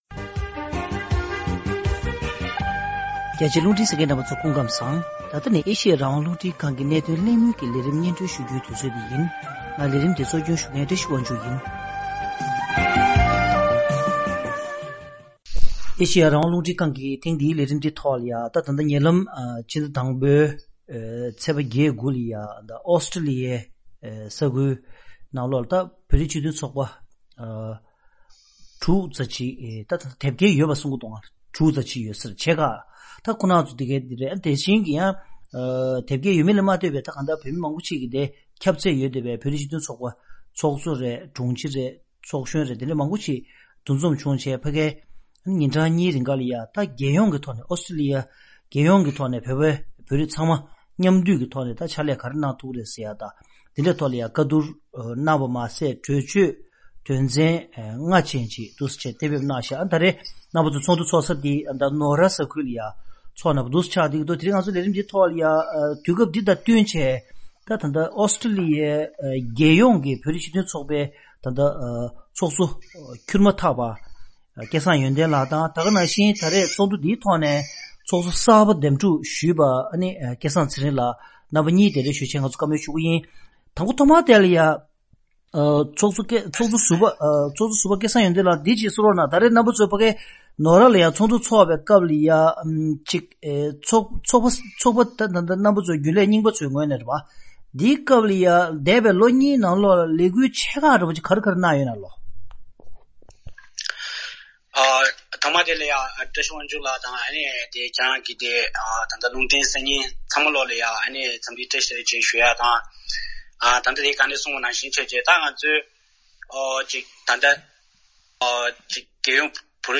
གཤམ་ལ་གནད་དོན་གླེང་མོལ་གྱི་ལས་རིམ་ནང་།